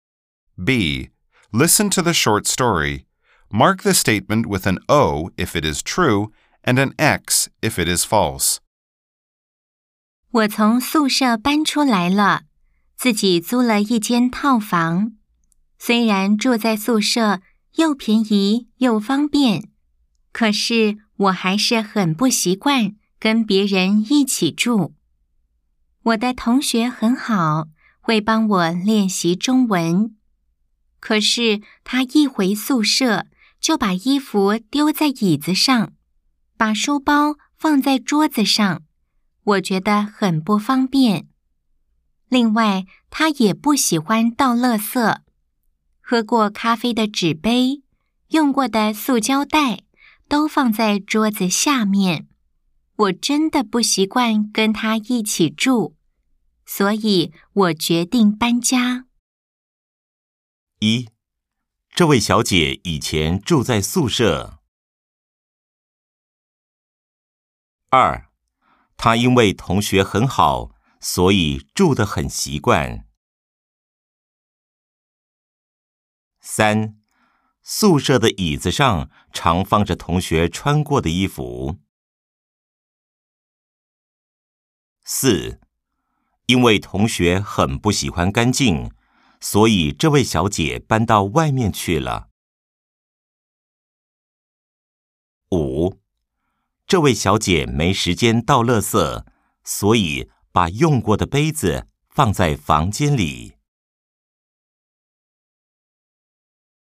B. Nghe câu chuyện ngắn.